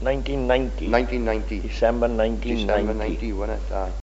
the-phonology-of-rhondda-valleys-english.pdf
5_3_3.1._nineteen_ninety.mp3